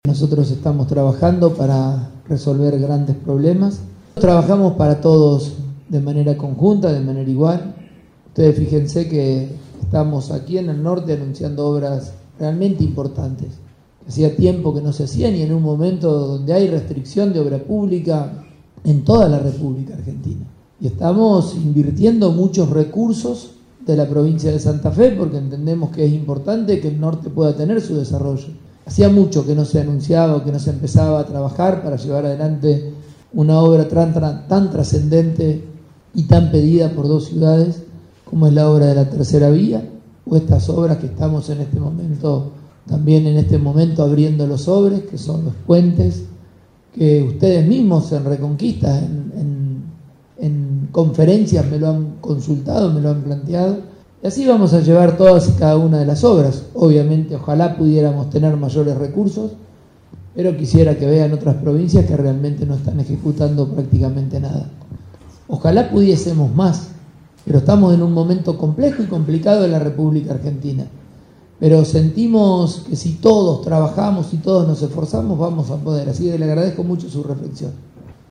MAXIMILIANO-PULLARO-Gobernador-de-Santa-Fe.mp3